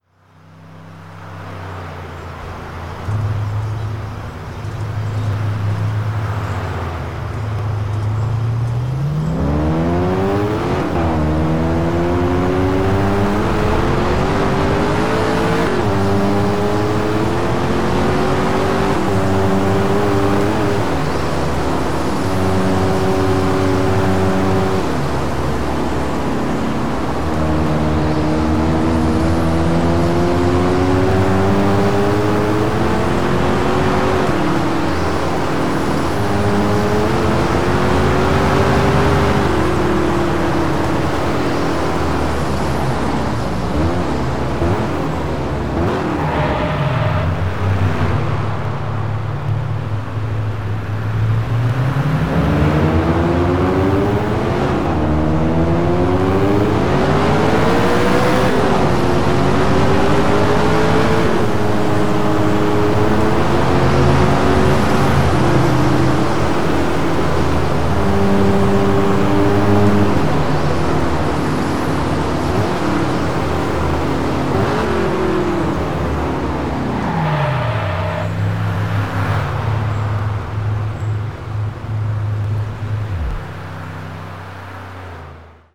- Aston Martin DB9